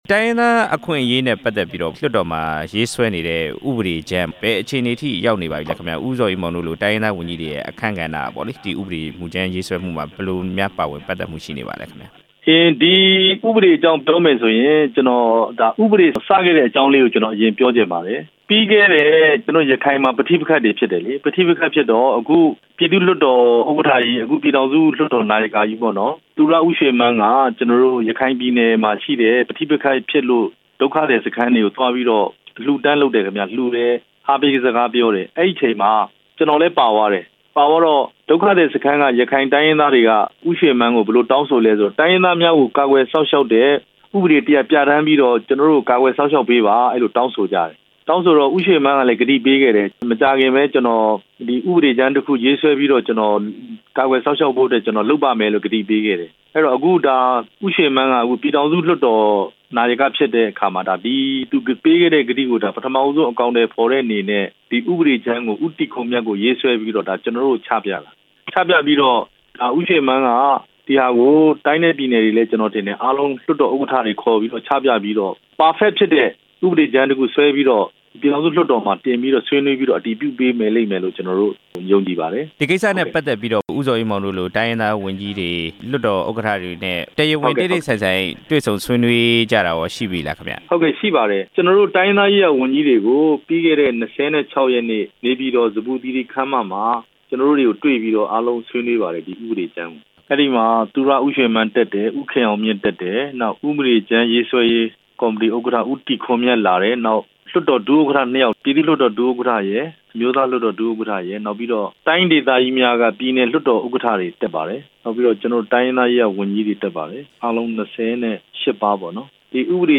ရခိုင်တိုင်းရင်းသားရေးရာဝန်ကြီး ဦးဇော်အေးမောင်နဲ့ ဆက်သွယ်မေးမြန်းချက်